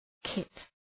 Προφορά
{kıt}